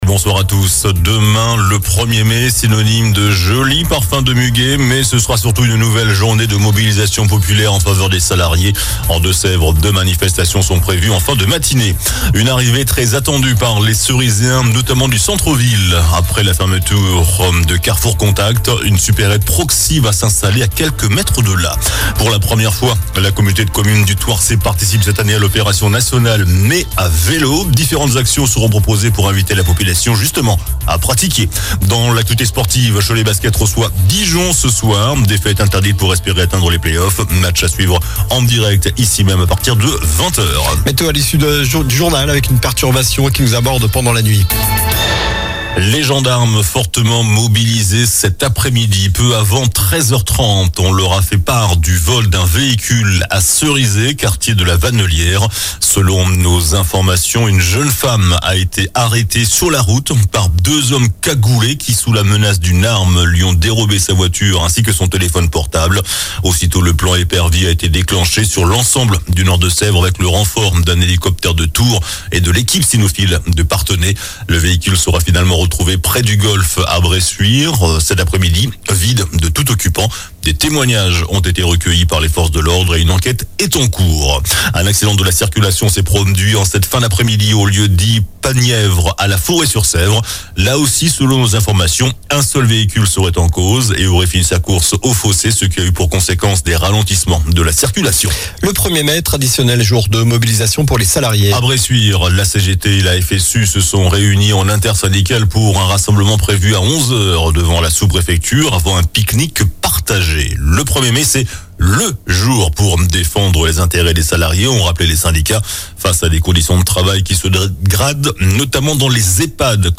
JOURNAL DU MARDI 30 AVRIL ( SOIR )